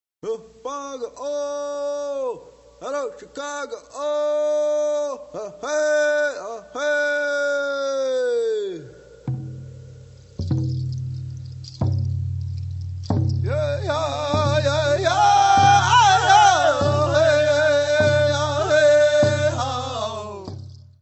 : stereo; 12 cm + folheto